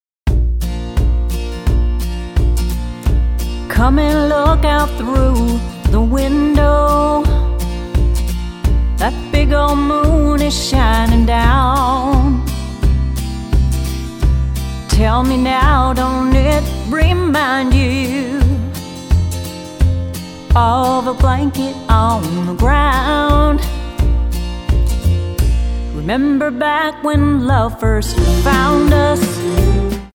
--> MP3 Demo abspielen...
Tonart:Bb-C Multifile (kein Sofortdownload.